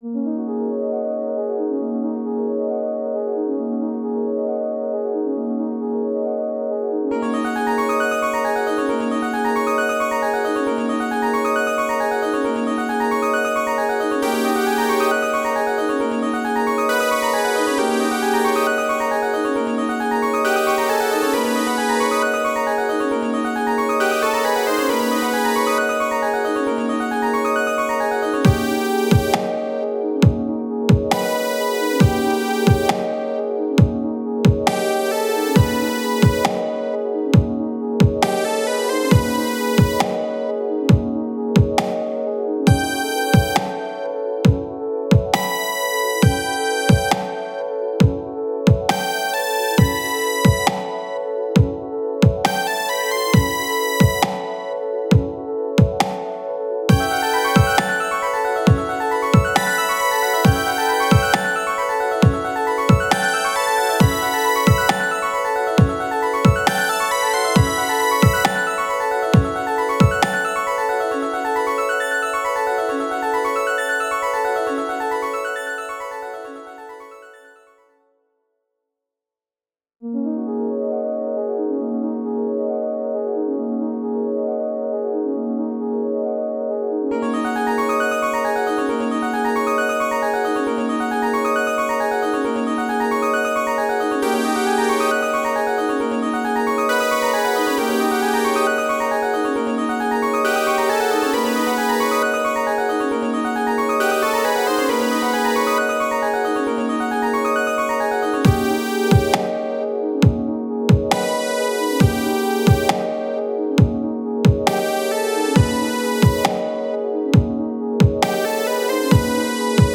Game Music
chill